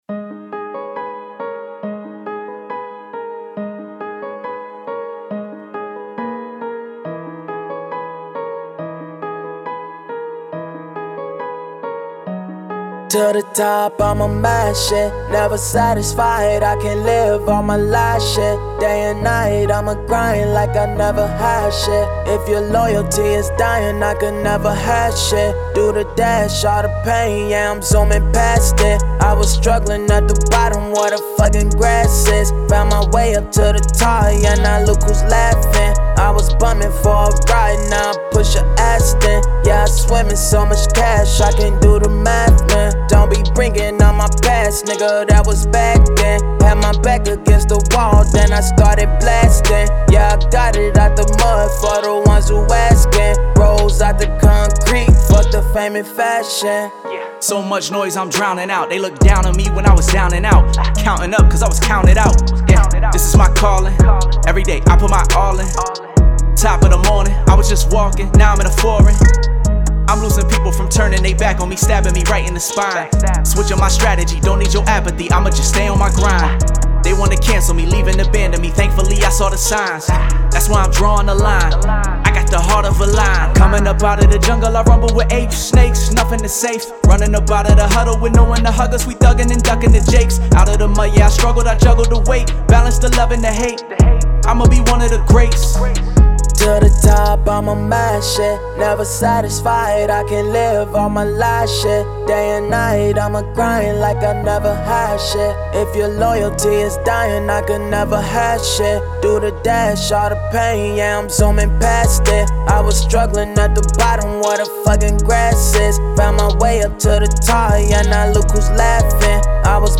Rap
Ab minor